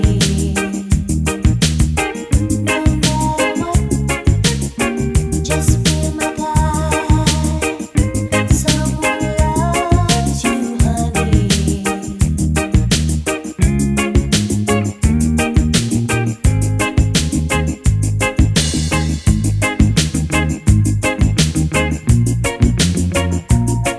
Duet Version Reggae 3:59 Buy £1.50